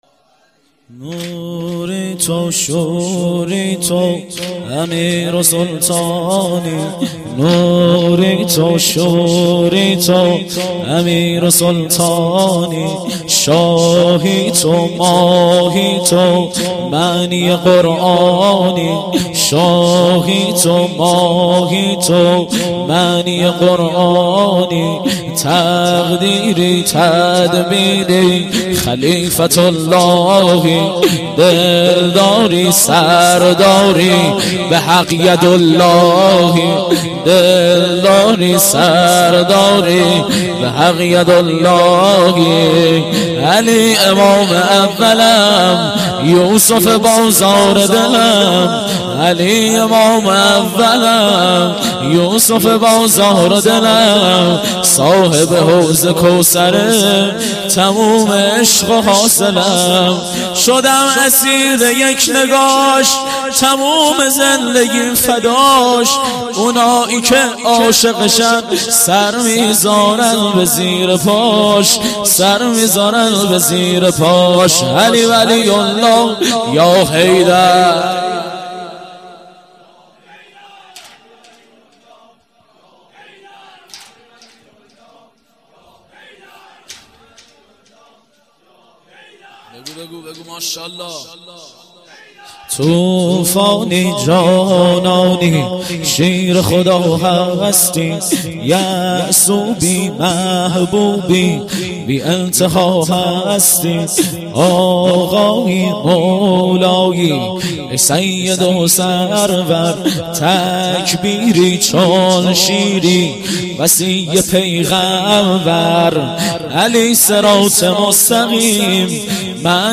واحد - نوری تو شوری تو